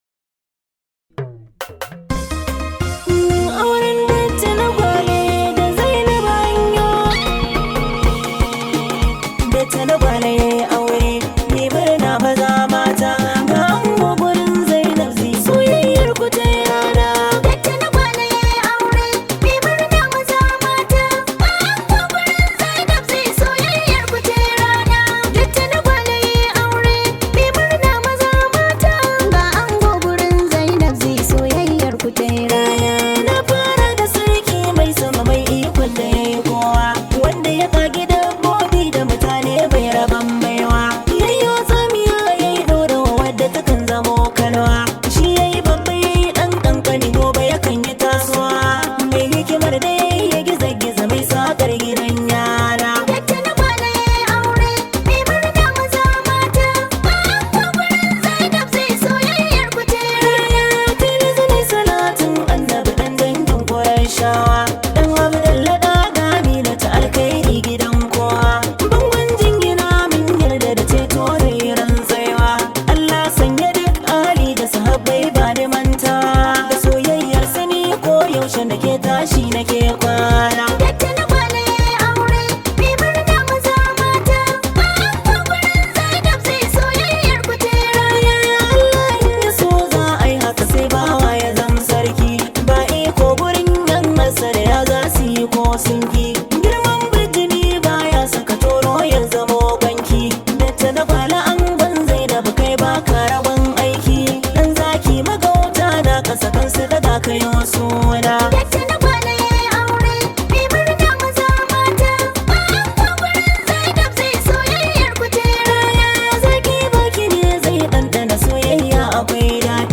Hausa Music
high vibe hausa song